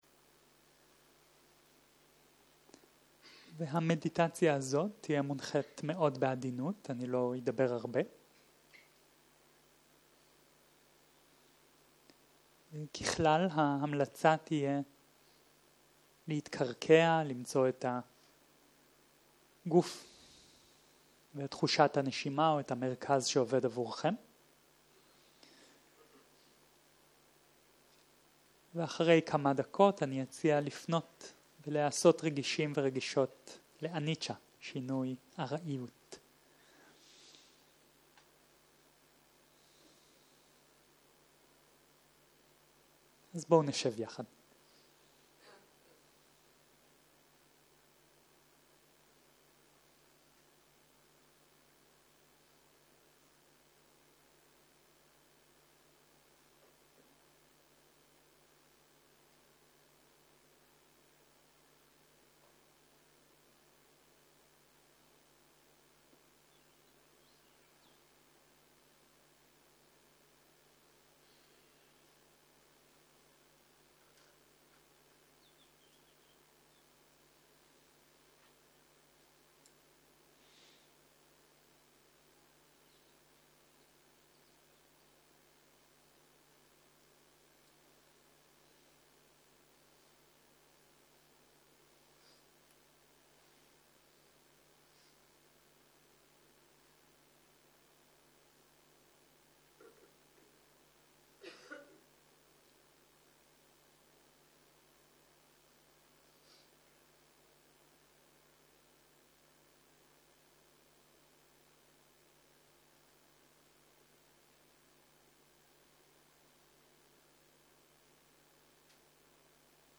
סוג ההקלטה: מדיטציה מונחית